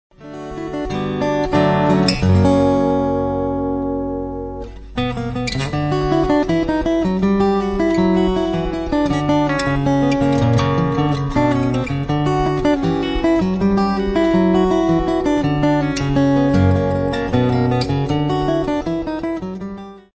Registrazione dal vivo
Milano, ex-chiesa degli Angioli, 15 Dicembre 2001.
voce narrante
chitarra, synth